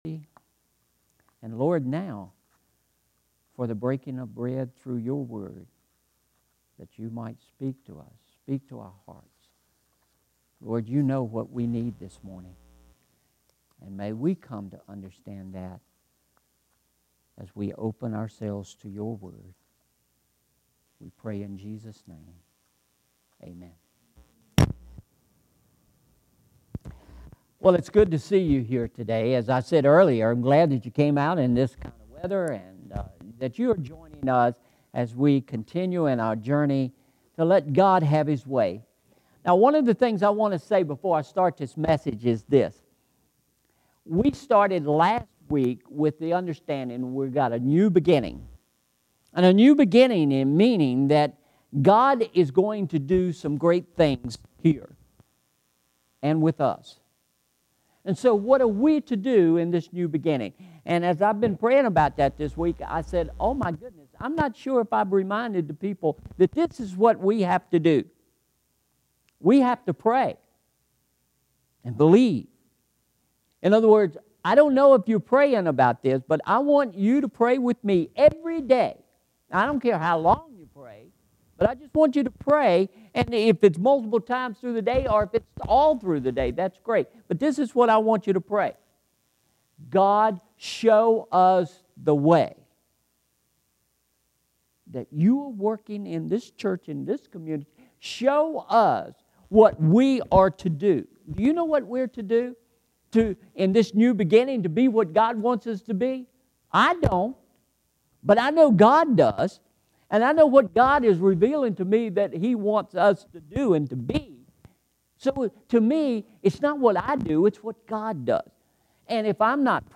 Sermon Series: “The Shack” Part 1